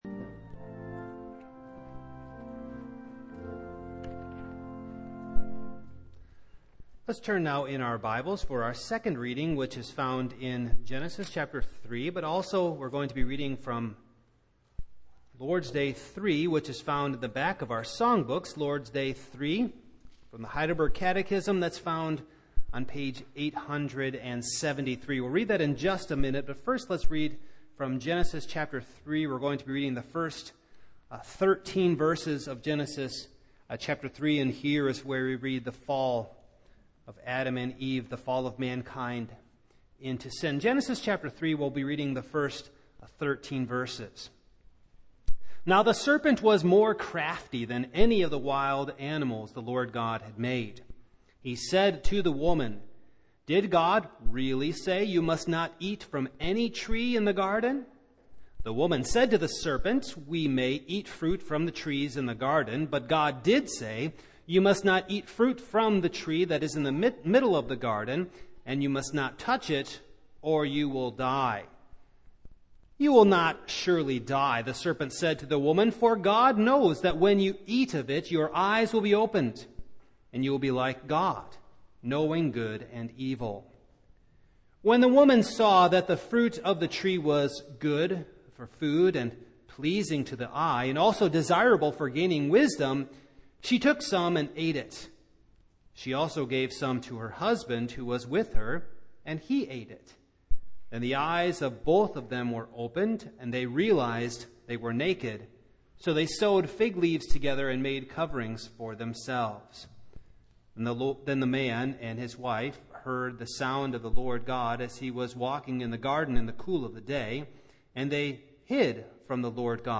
Heb. 2:14-18 Service Type: Evening